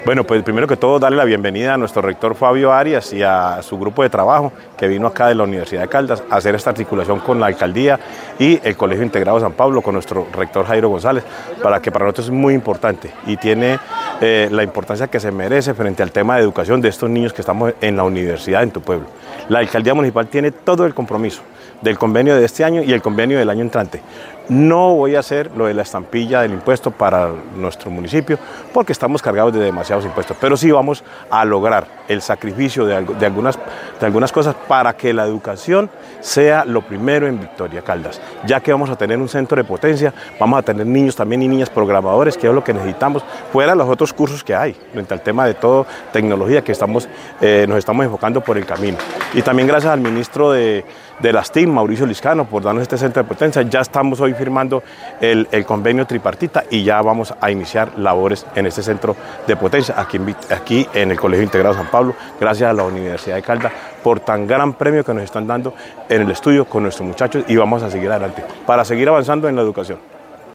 -Audio Alcalde de Victoria, Juan Alberto Vargas Osorio.
alcalde-Victoria-1.mp3